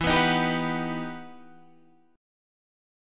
EmM9 Chord
Listen to EmM9 strummed